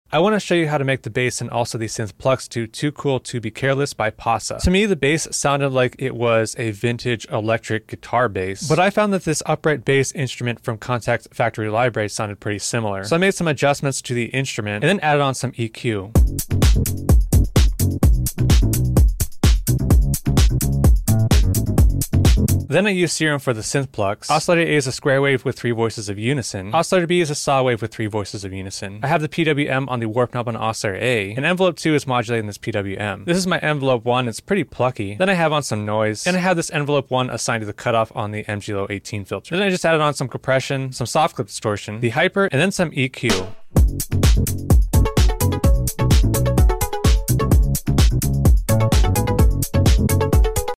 Bass & Synth Plucks
synth, sound design, tutorial